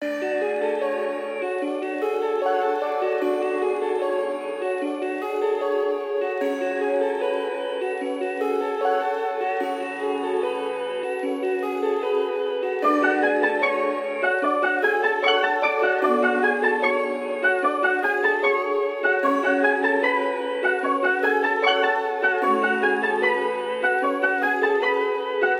Tag: 150 bpm Trap Loops Bells Loops 4.31 MB wav Key : D FL Studio